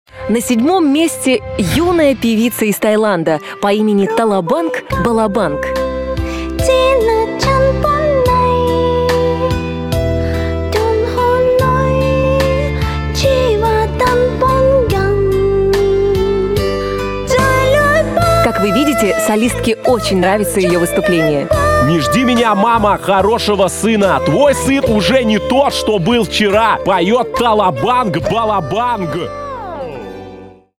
мужской голос
женский голос
вживую
цикличные
тайские